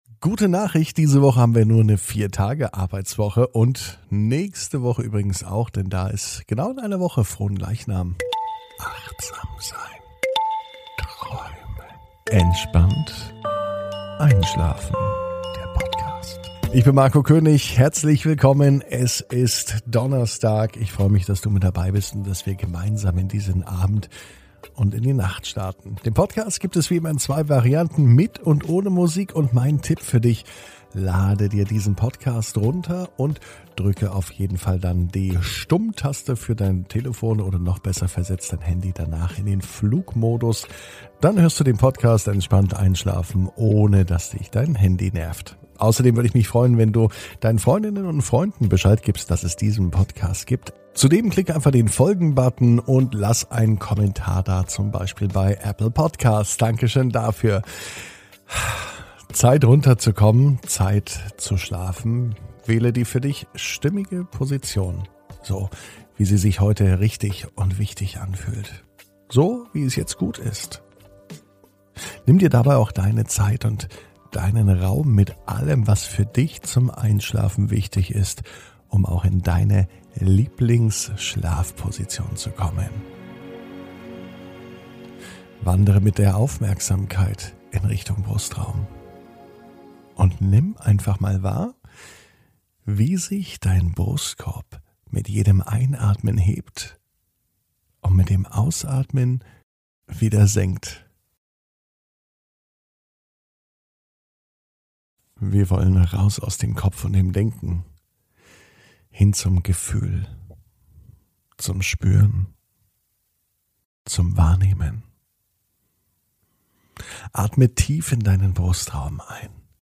(Ohne Musik) Entspannt einschlafen am Donnerstag, 27.05.21 ~ Entspannt einschlafen - Meditation & Achtsamkeit für die Nacht Podcast